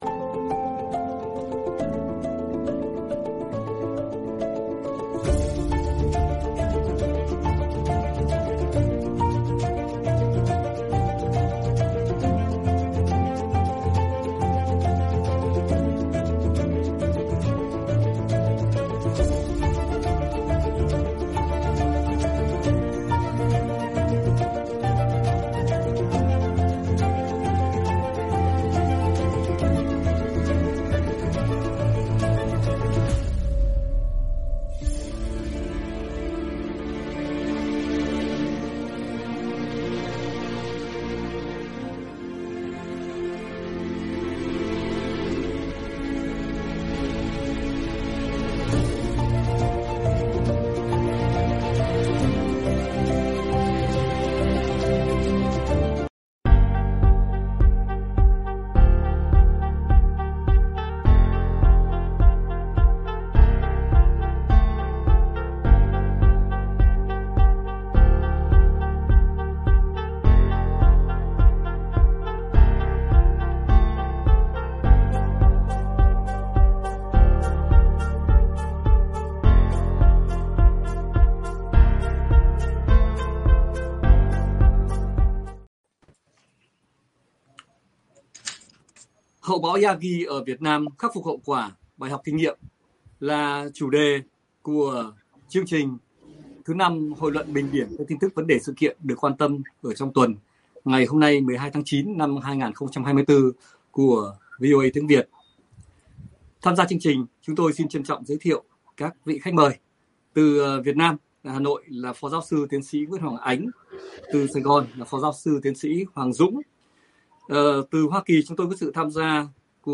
Các nhà quan sát, bình luận thời sự từ Việt Nam và hải ngoại bình điểm các tin tức, vấn đề, sự kiện đáng chú ý, quan tâm trong tuần tại Hội luận thứ Năm ngày 12/9/2024 của VOA Tiếng Việt.